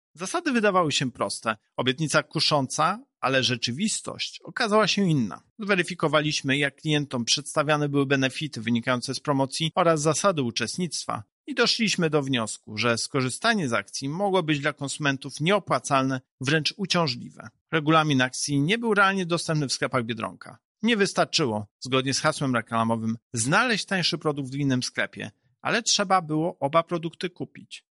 • mówi Tomasz Chróstny, prezes UOKiK.